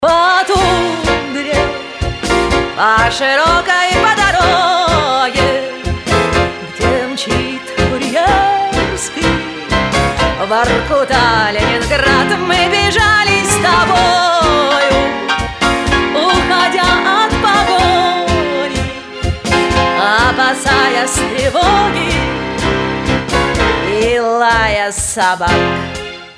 292 Категория: Нарезки шансона Загрузил